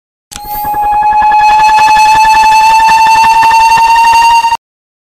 payday-2-sound-cloaker-scream-youtubemp3free.mp3